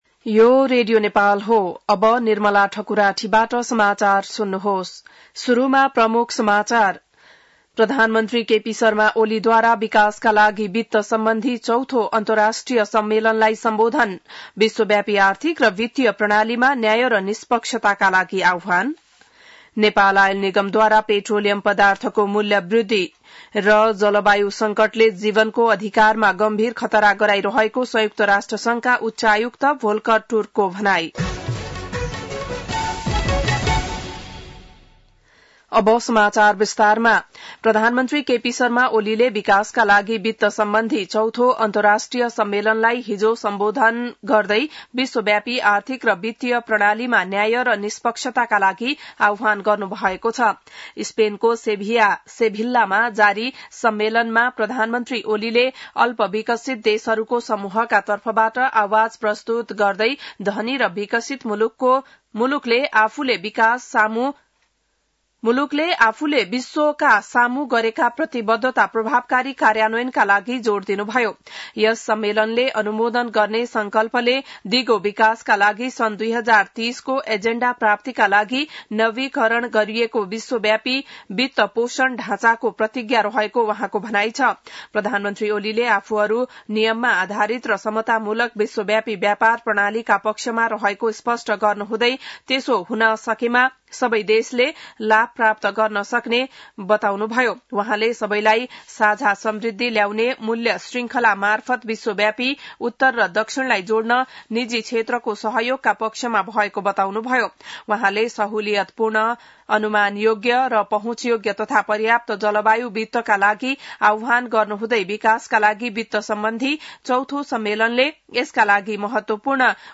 बिहान ९ बजेको नेपाली समाचार : १७ असार , २०८२